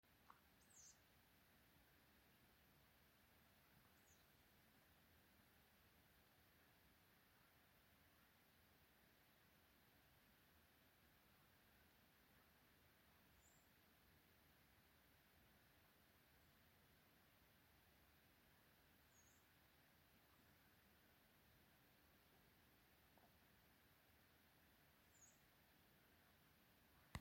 Ausainā pūce, Asio otus
Administratīvā teritorijaKrustpils novads
StatussTikko šķīlušies mazuļi vai vecāki ar mazuļiem (RM)